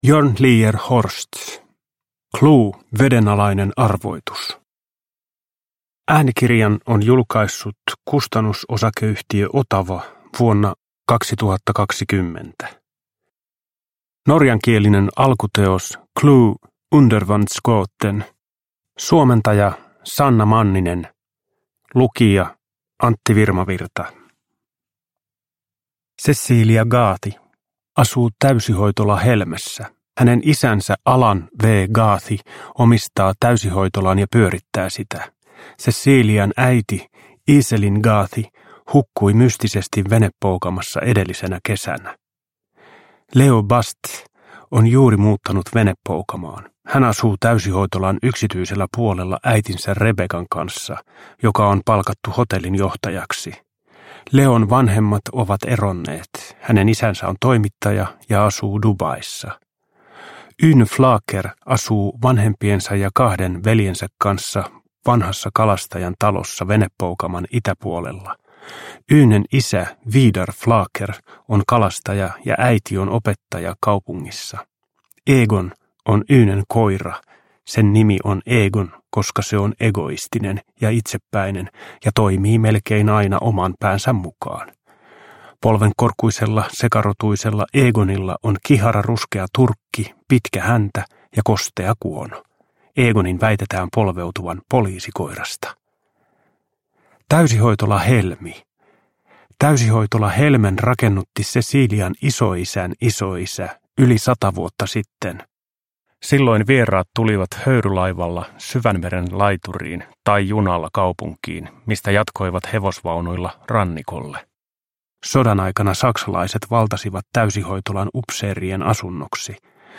CLUE - Vedenalainen arvoitus – Ljudbok – Laddas ner